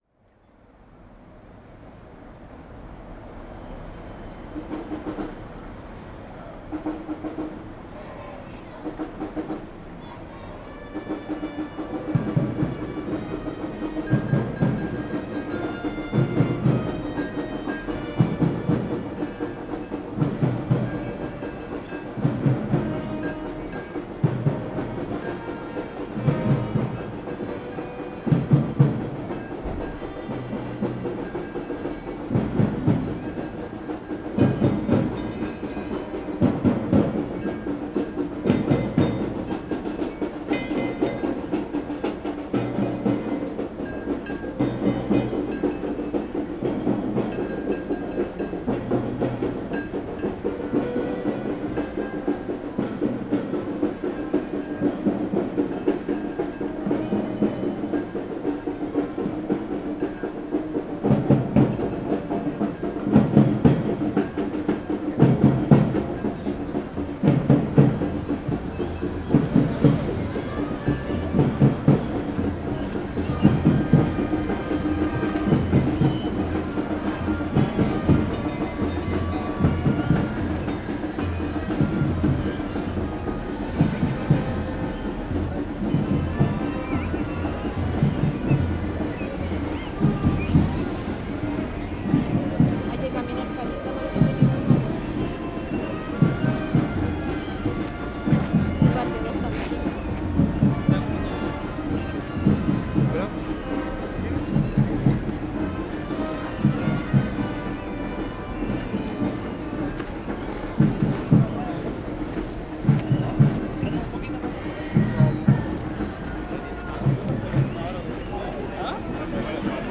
Puis, des trompettes et des tambours ont commencé à jouer devant mon appartement. J’ai enregistré les sons pour que cet ailleurs devienne mon ici.
Au final,  je tente de mêler les deux expériences à coups d’effets et de redites. C’est cacophonique.
[Santiago : Alameda, al borde del cerro Santa Lucía (octubre 2018) + Parque Bustamante (Día de Todos los Santos, 2018)]